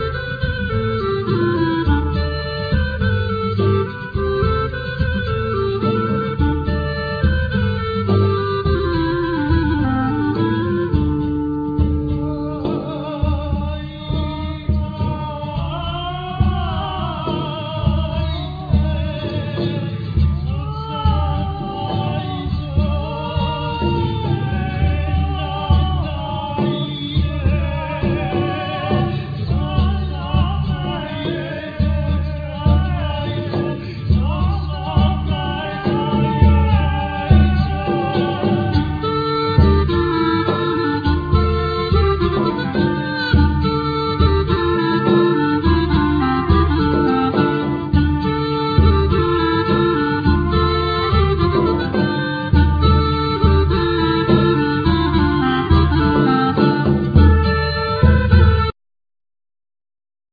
Guitar,Clarinetto,Oboe,Flute,Tamburo,Vocal
Tres cumano,Tammorra a sonagli,Chorus
Percussions,Sonagli,Timpani,Chorus
Darbouka,Chorus
Violin,Chorus